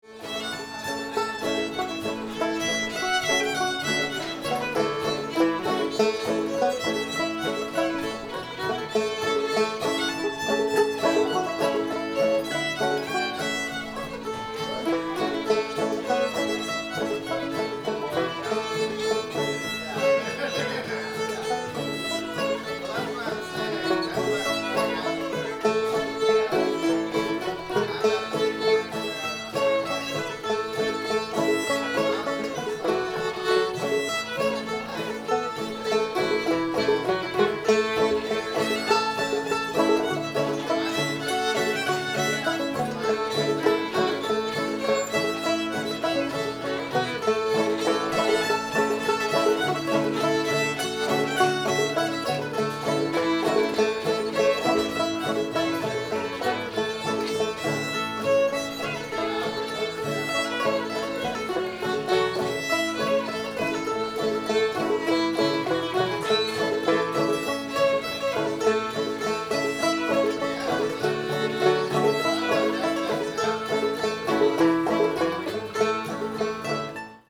fire on the mountain [A modal]